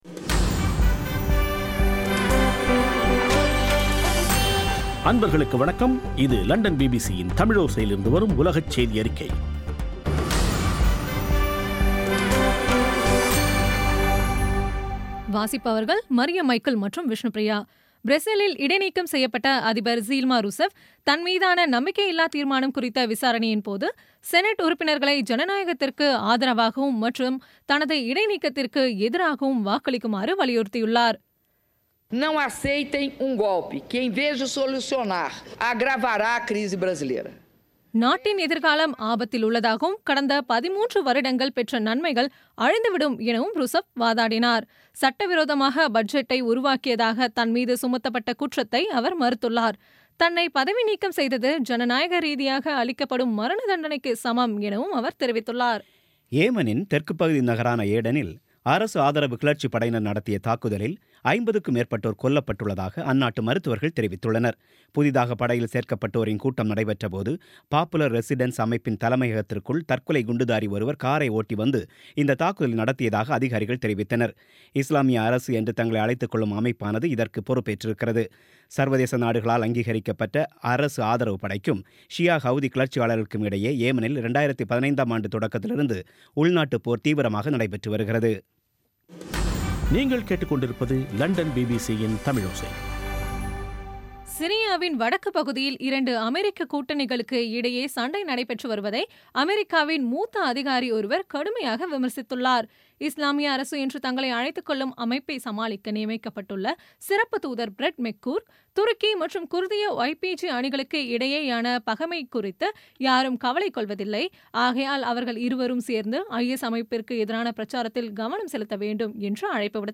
பிபிசி தமிழோசை செய்தியறிக்கை (29/08/16)